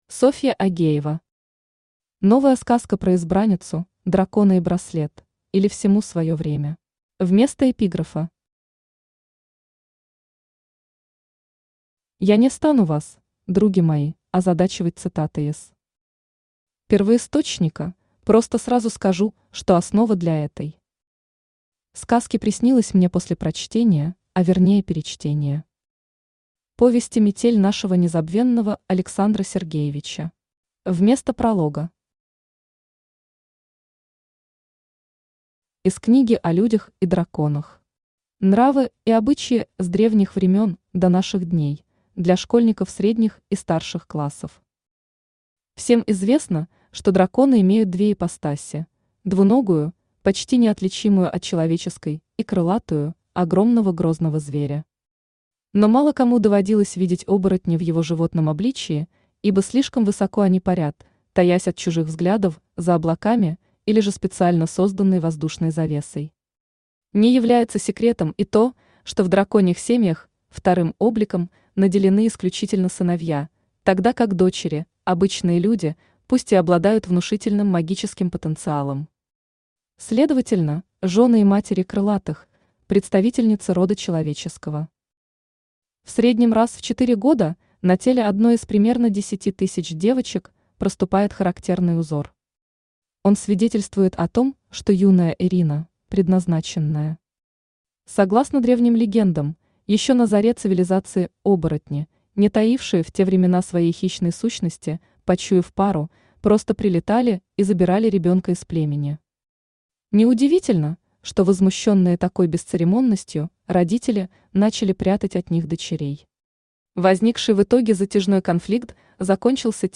Аудиокнига Новая сказка про избранницу, дракона и браслет, или Всему свое время | Библиотека аудиокниг
Aудиокнига Новая сказка про избранницу, дракона и браслет, или Всему свое время Автор Софья Агеева Читает аудиокнигу Авточтец ЛитРес.